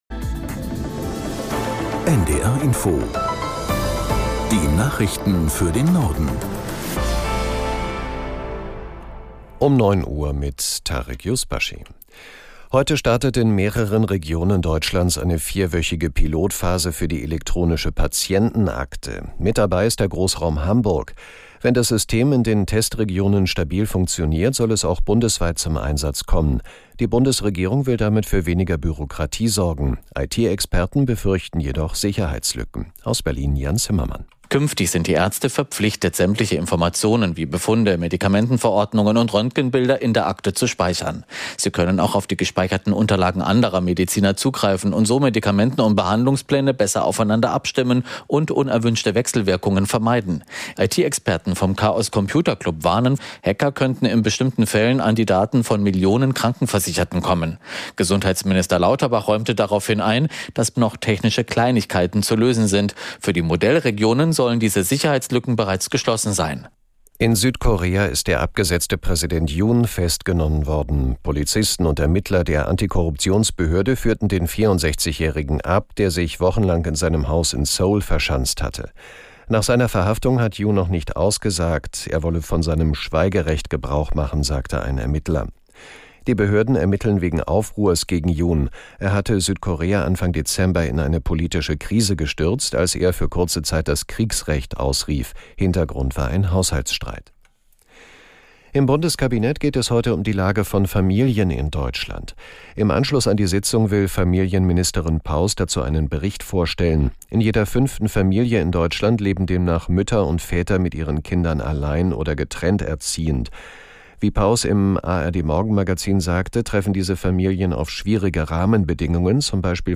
Nachrichten - 15.01.2025